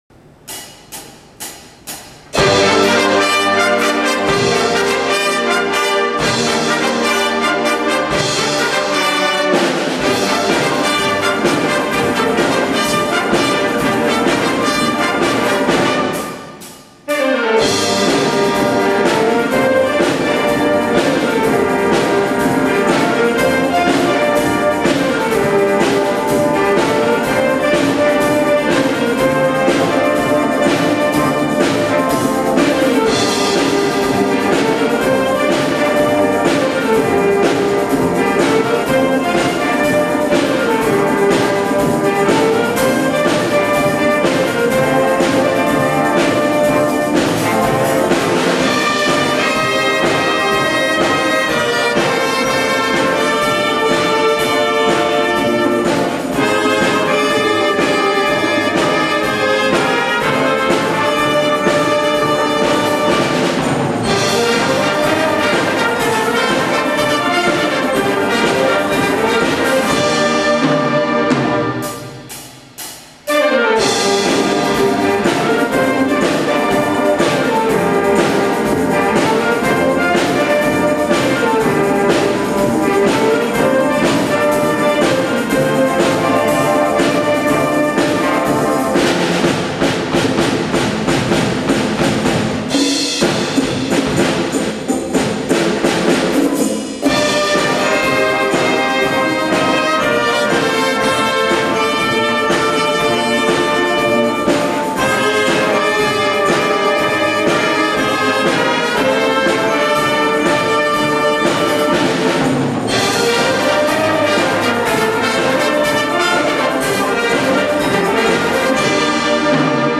時々ライブで使わせてもらっています.